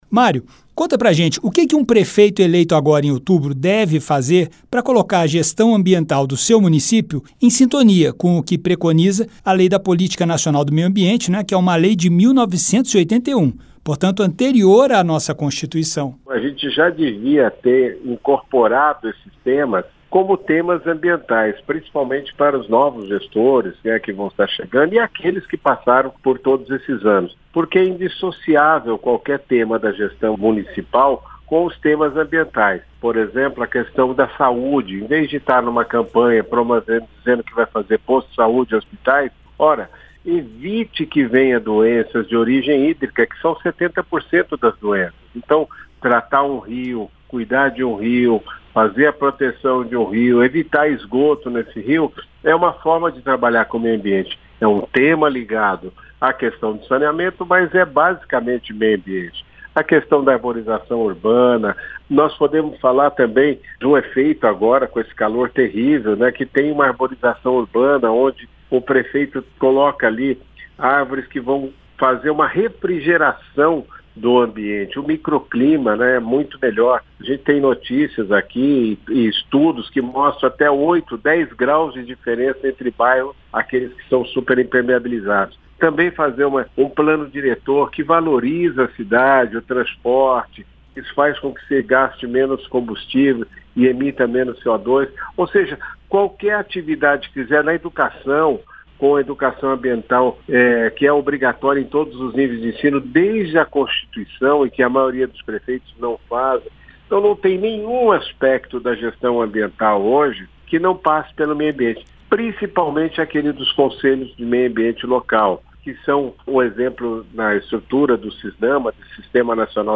Ambientalista explica o que os novos prefeitos precisarão fazer para se adequar à Política Nacional de Meio Ambiente